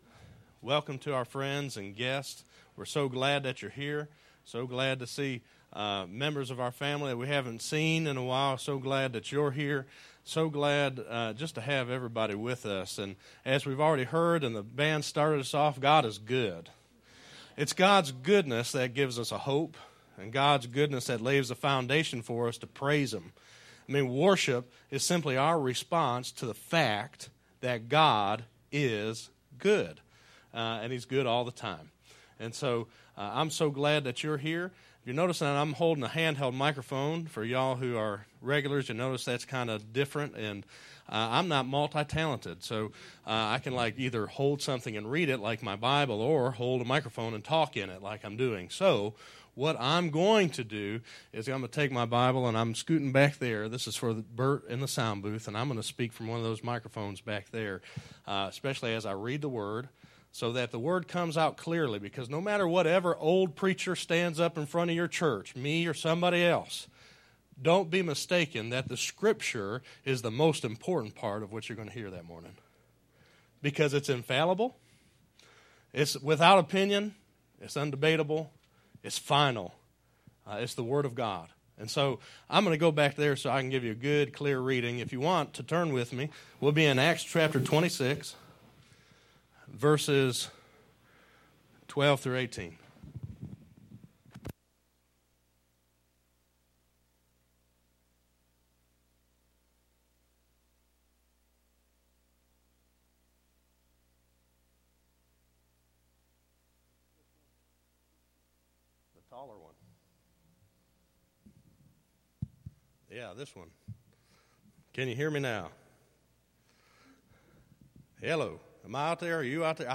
6_24_12_Sermon.mp3